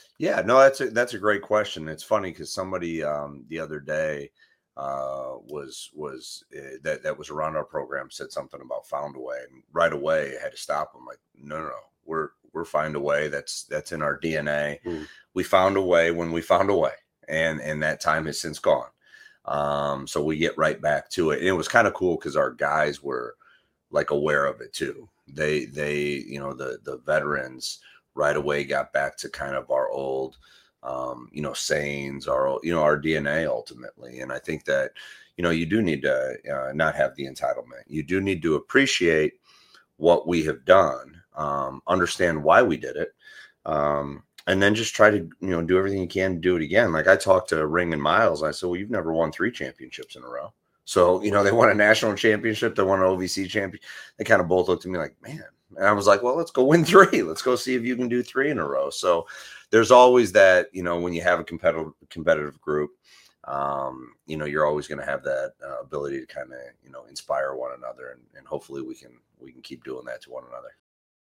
Part One – August Conversation